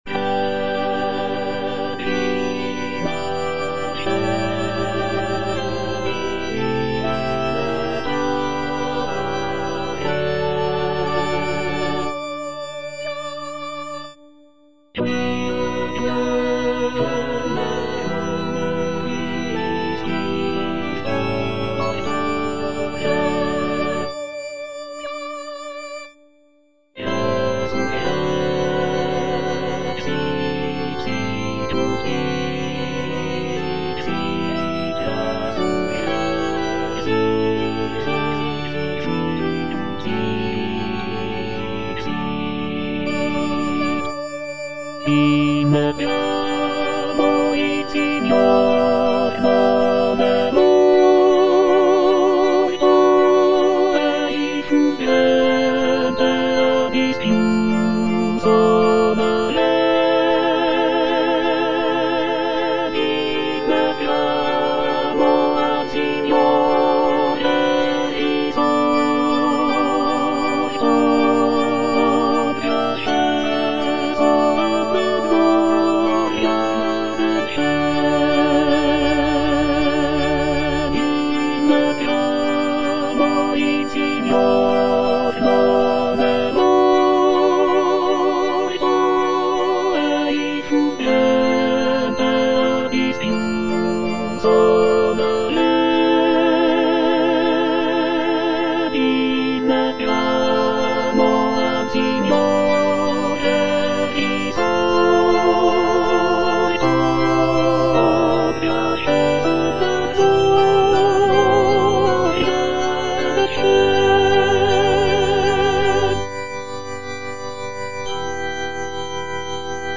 External choir, tenor I (Emphasised voice and other voices)